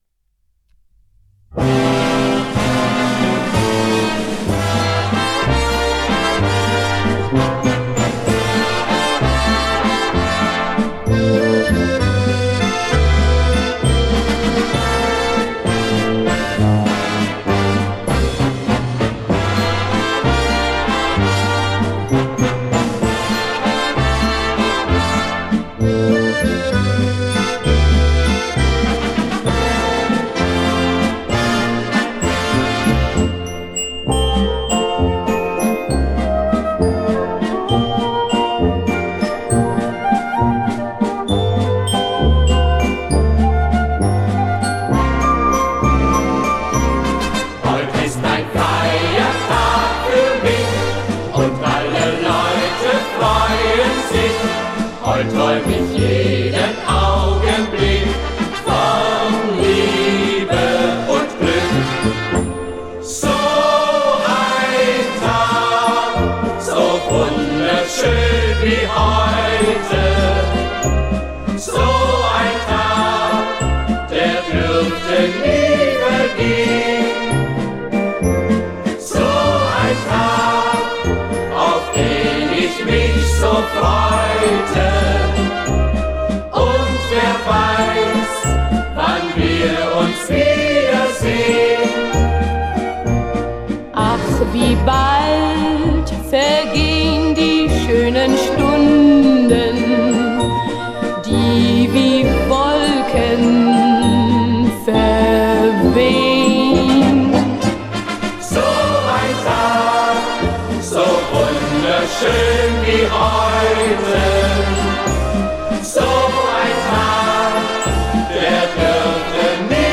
Австрийский певец.